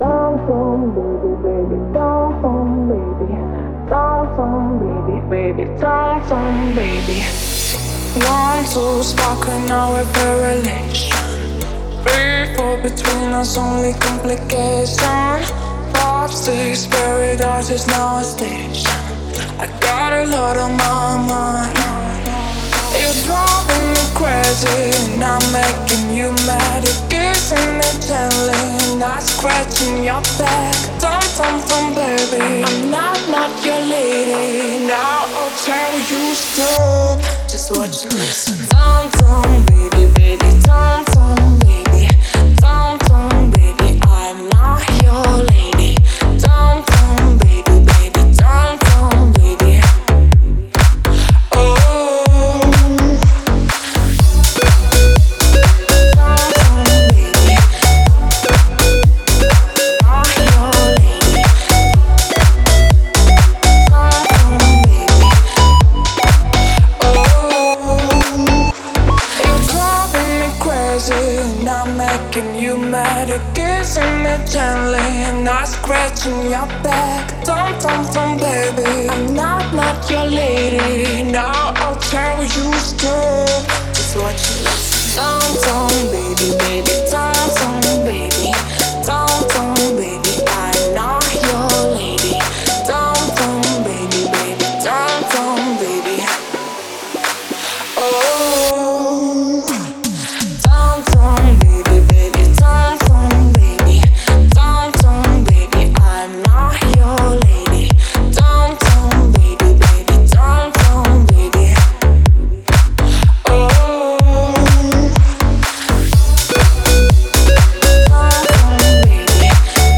динамичная поп-песня